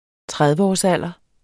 Udtale [ ˈtʁaðvəɒs- ]